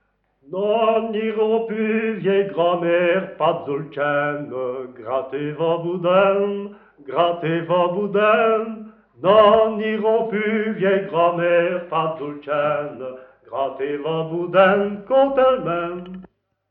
Genre : chant
Type : chanson narrative ou de divertissement
Lieu d'enregistrement : Monceau-Imbrechies
Support : bande magnétique